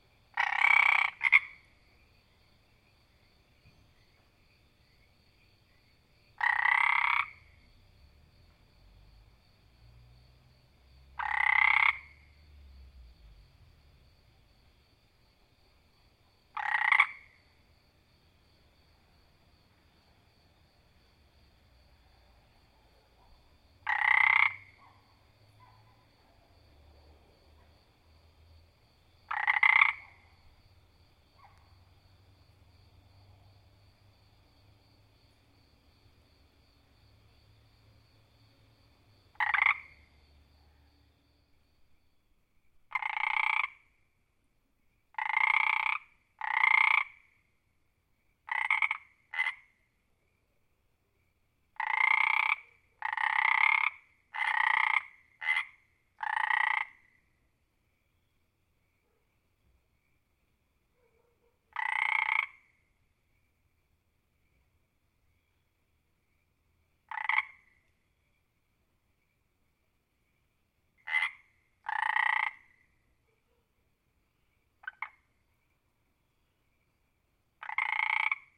frog-sound